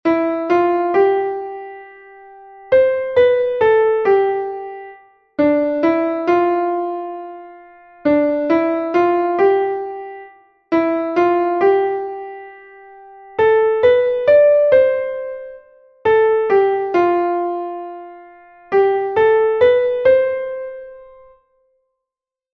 Here there are four six eight time signature exercises.